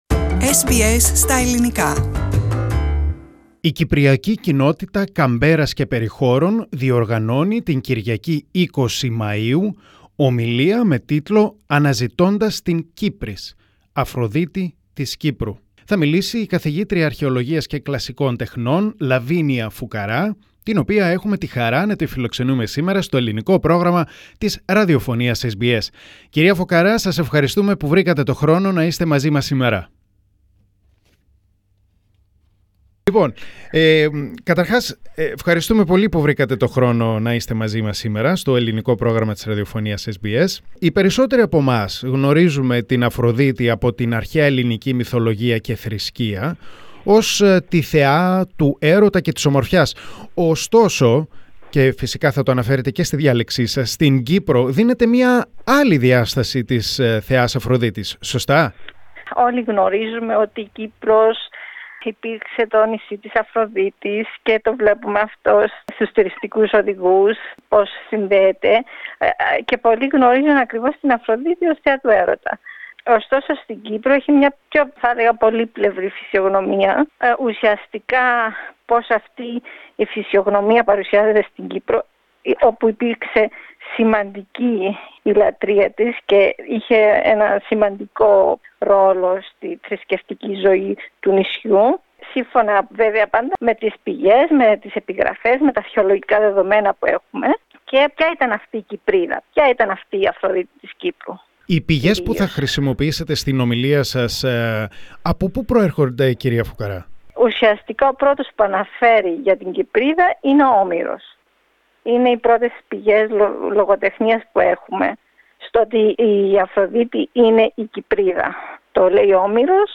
SBS Greek